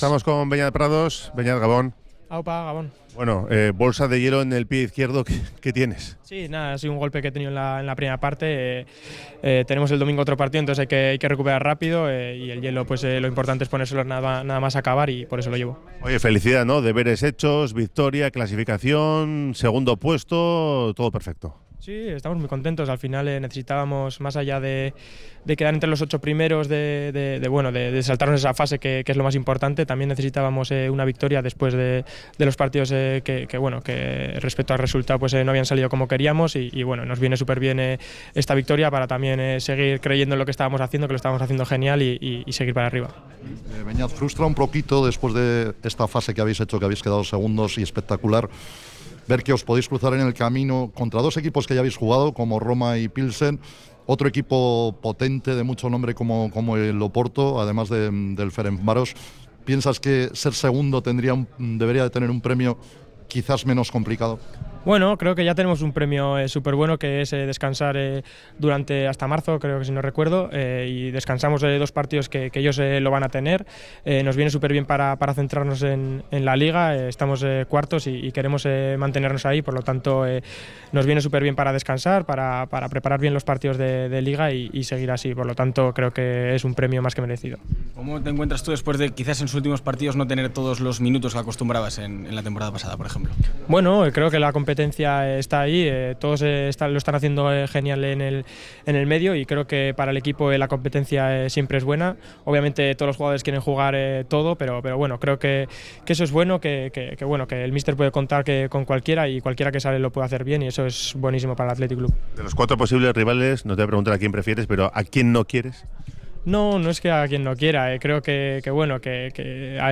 El centrocampista del Athletic Club, Beñat Prados, ha analizado en zona mixta la clasificación al top 8 y su situación personal dentro de la plantilla.